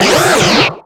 Cri de Venalgue dans Pokémon X et Y.